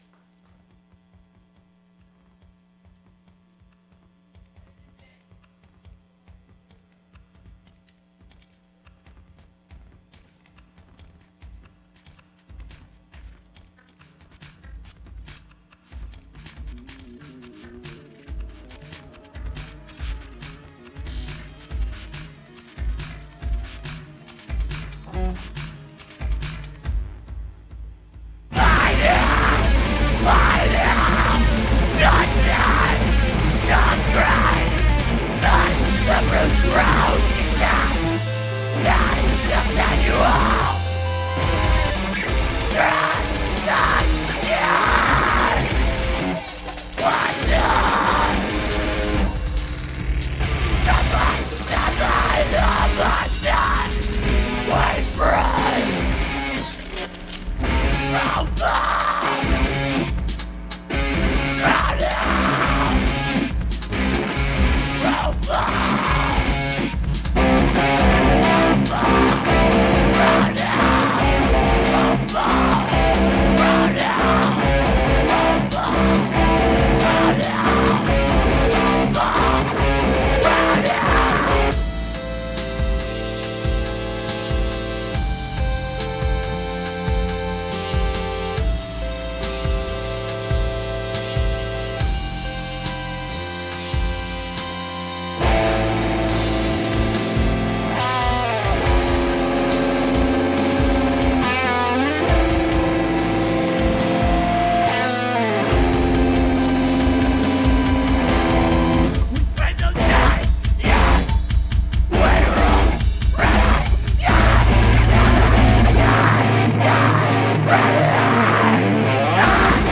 Just ripping the thing to pieces.
Seattle, WA